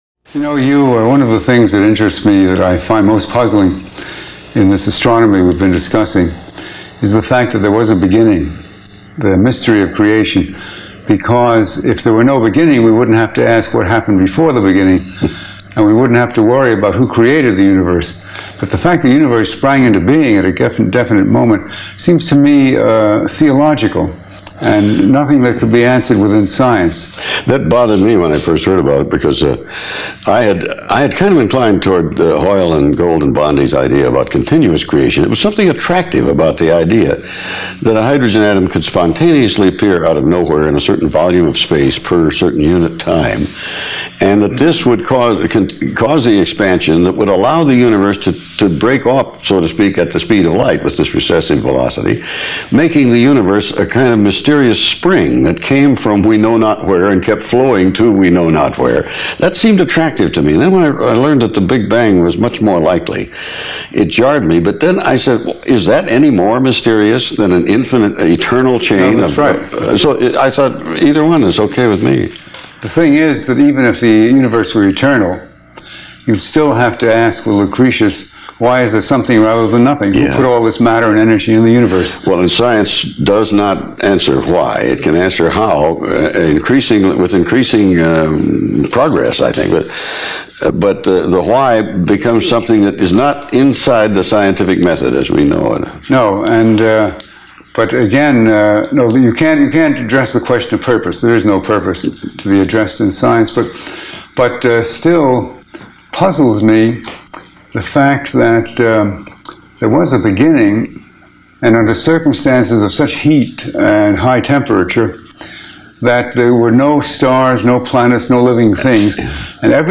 Join legendary broadcaster Hugh Downs as he talks with globally renowned astronomer and former director of the Mt. Wilson Institute, Robert Jastrow in the latest episode of the Free To Choose Media Podcast, Science and Religion.